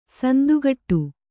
♪ sandugaṭṭu